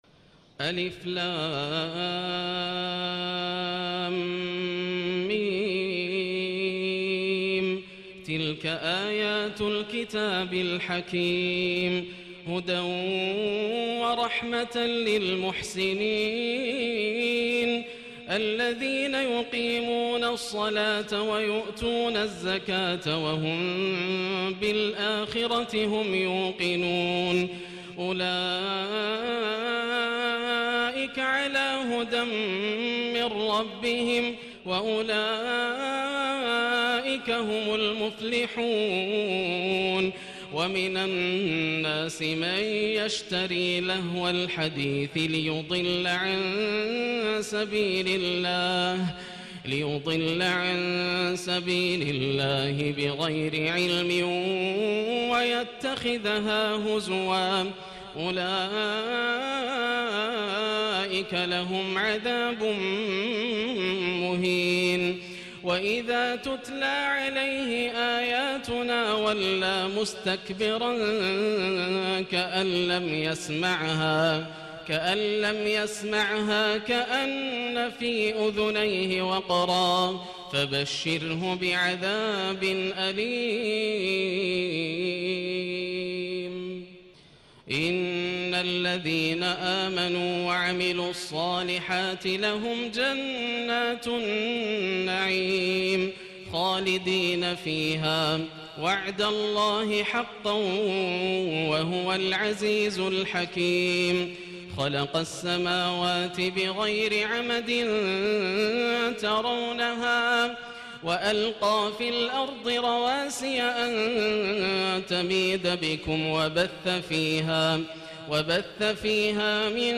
الليلة الأخيرة من تراويح رمضان 1437هـ > الليالي الكاملة > رمضان 1437هـ > التراويح - تلاوات ياسر الدوسري